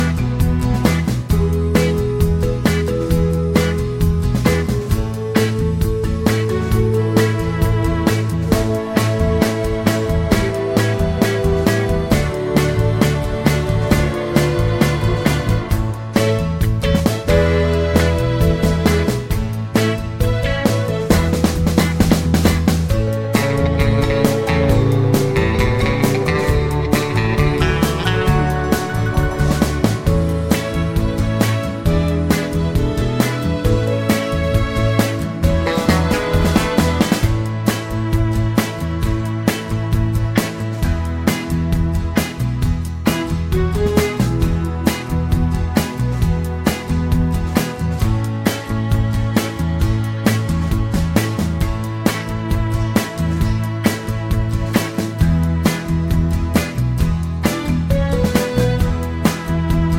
no Backing Vocals Crooners 2:45 Buy £1.50